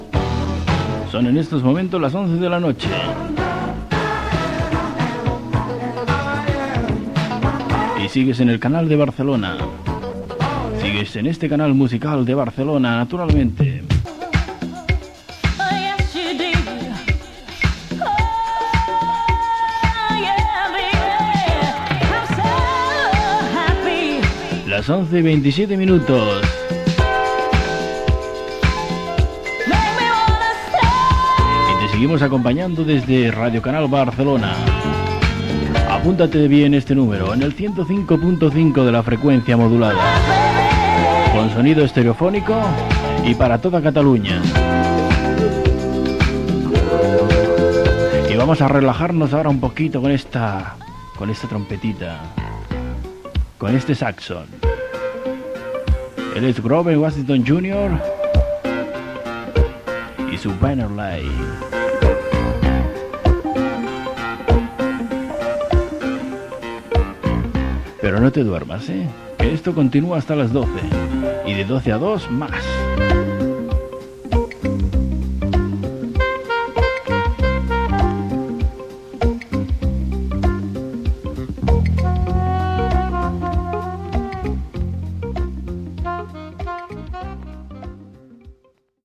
Identificaciò i temes musicals